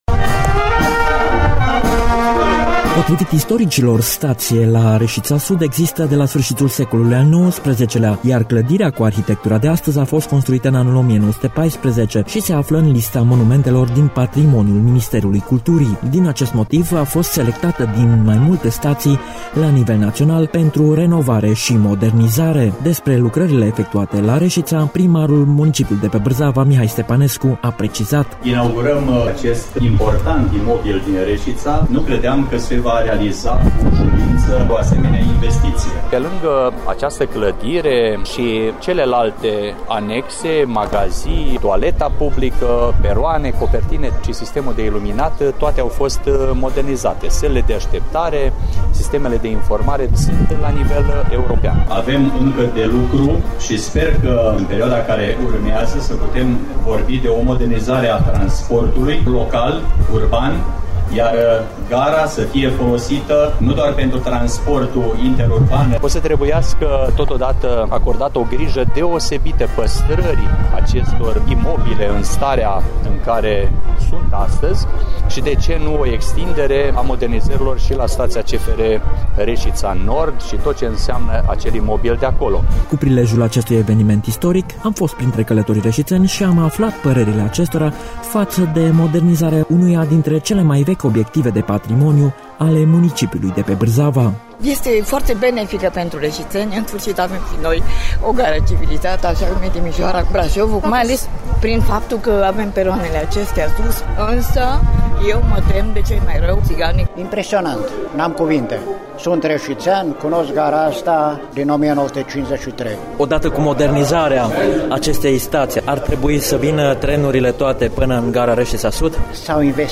Reşiţenii, adunaţi pe peroane, s-au declarat mulţumiţi de noul aspect al staţiei din oraşul vechi, dar au ţinut să precizeze că, la câte investiţii s-au făcut, aducând fosta haltă de mişcare a Gării Reşiţa Nord la un standard european, toate trenurile, fie ele private sau nu, ar trebui să ajungă şi să oprească la Reşiţa Sud.